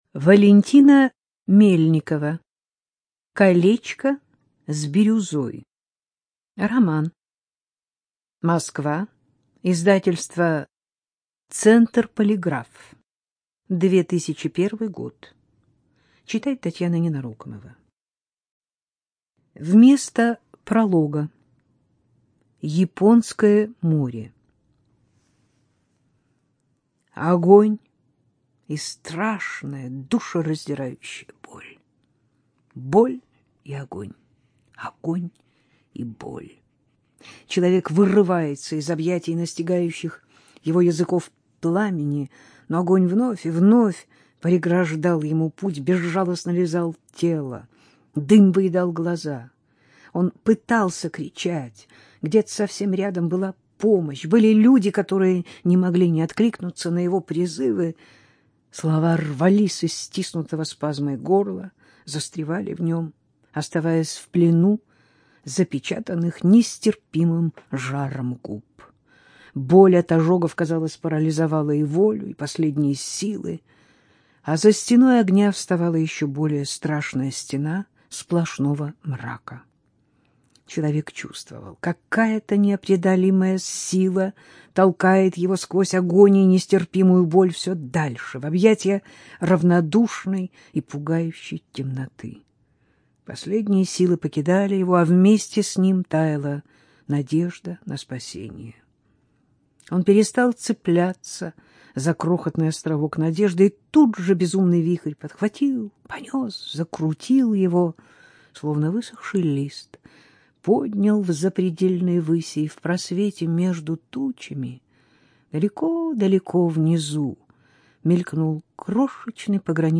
Студия звукозаписизвукотэкс